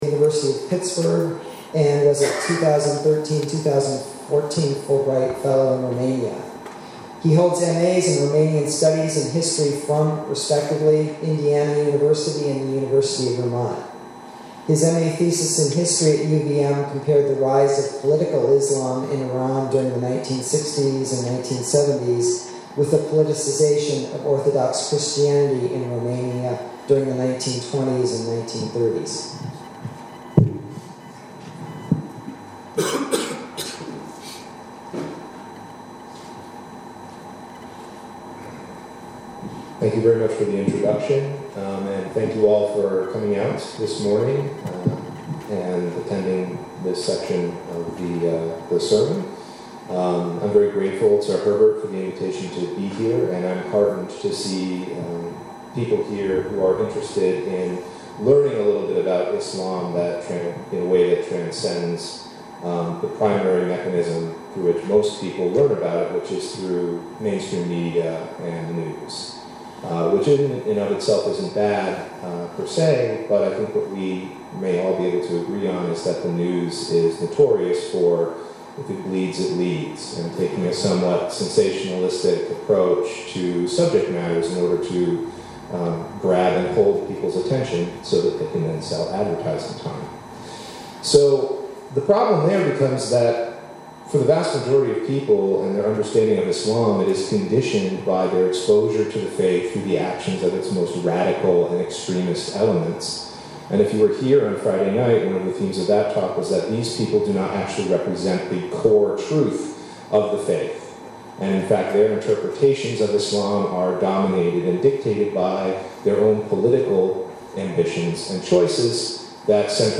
Posted in Sermons | Leave a Comment »